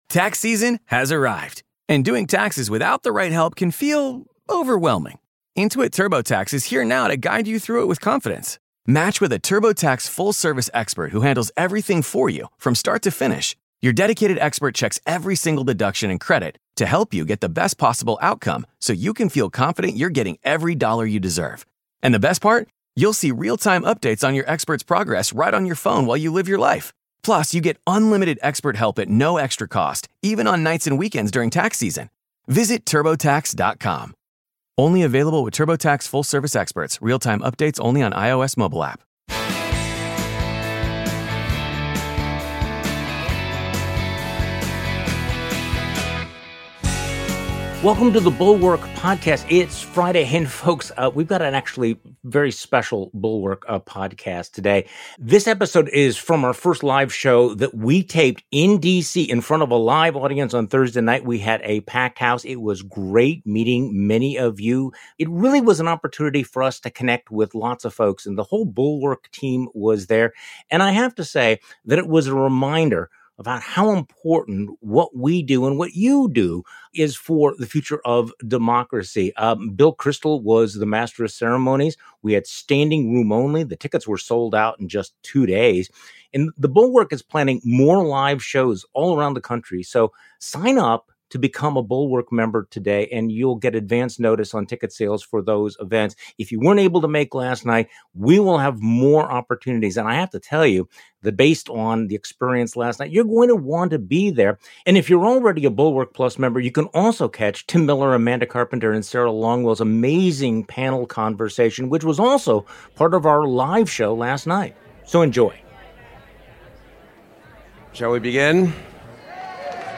At our first live show, Michael Fanone shared his thoughts about Kevin McCarthy, Lindsey Graham, Mike Pence, Fox News, and the battle for the American soul. The weekend pod with Charlie Sykes.